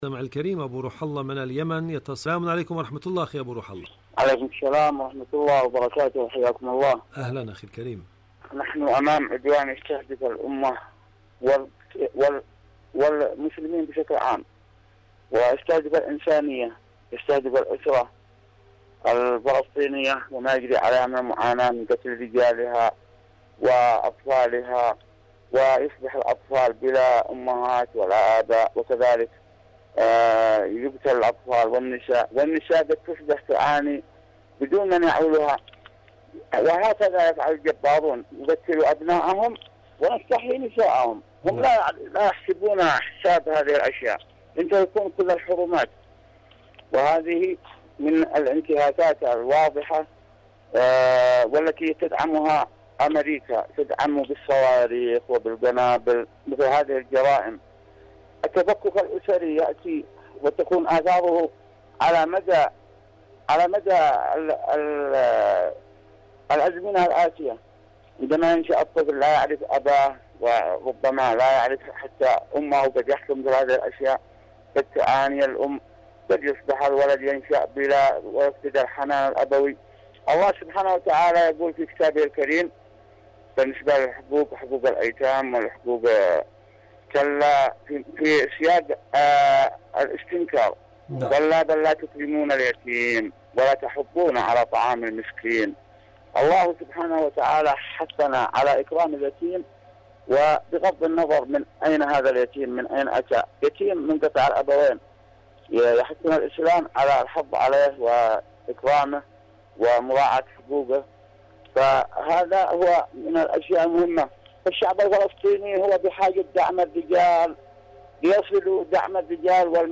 مشاركة صوتية
إذاعة طهران- معكم على الهواء